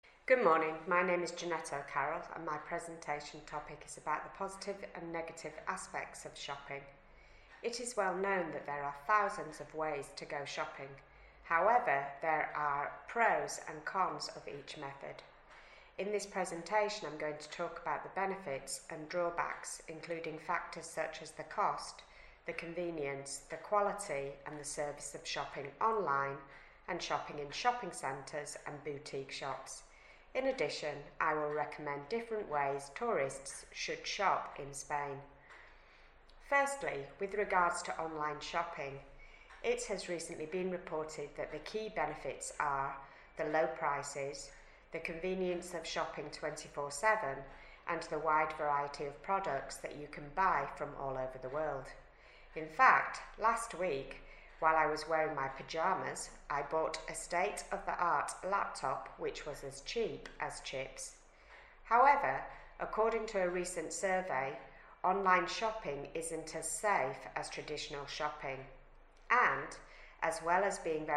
2: This model presentation responds to the following EOI B2 exam question: